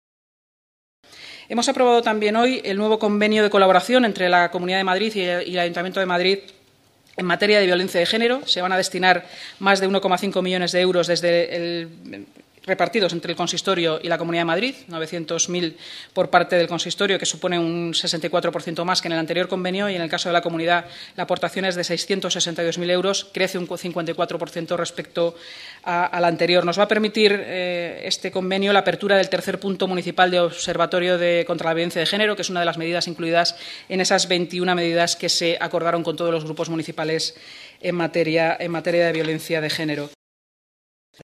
Nueva ventana:Palabras de la delegada de Seguridad y Emergencias y portavoz del Ayuntamiento, Inmaculada Sanz, esta mañana, tras la celebración de la Junta de Gobierno: